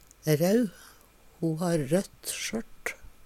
rau - Numedalsmål (en-US)